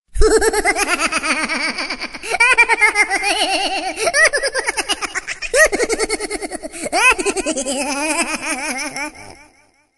Kategória: Vicces